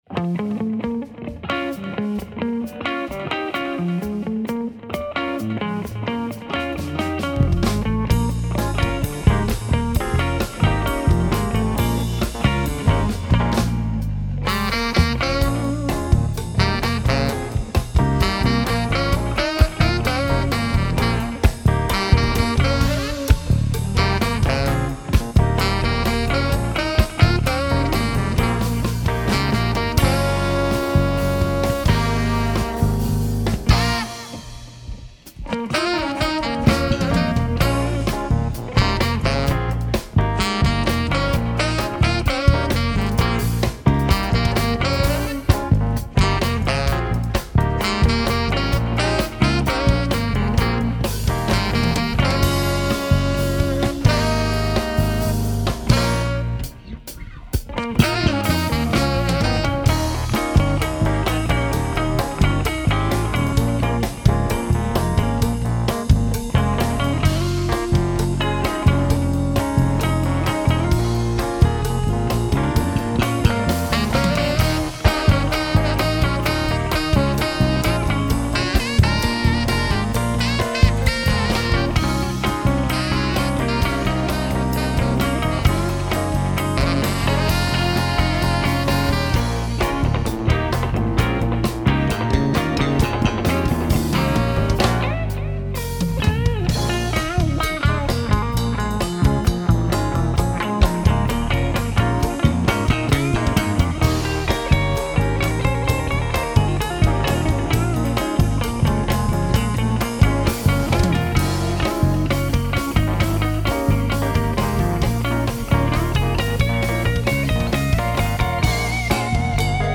Jazz-Fusion